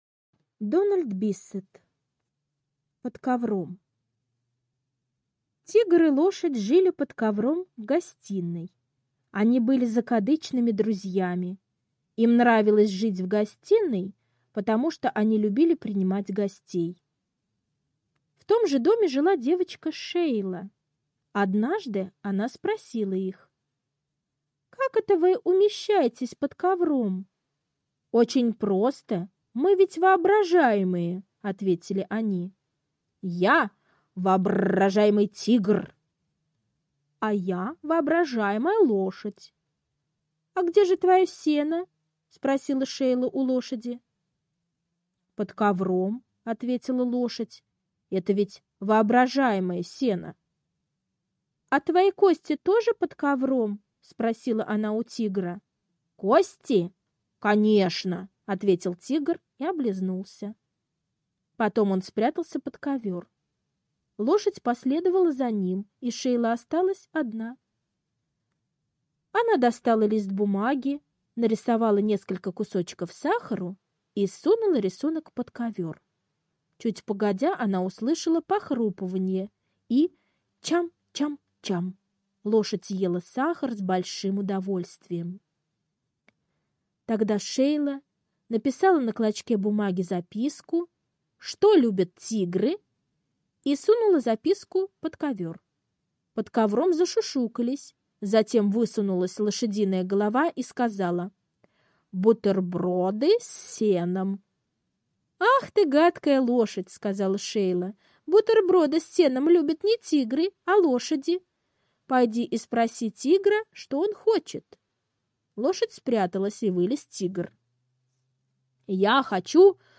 Под ковром - аудиосказка Биссета Д. Сказка про лошадь и тигра, которые жили под ковром, потому что они были воображаемые…